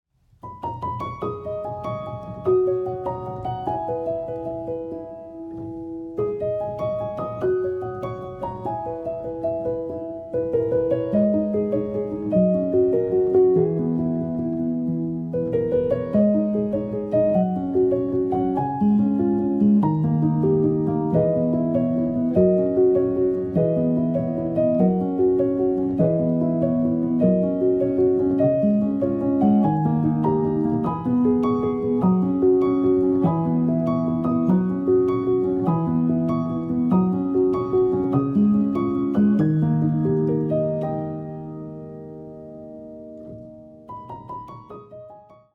延々と続く桜並木を歩む夢を見るようなアルバムです。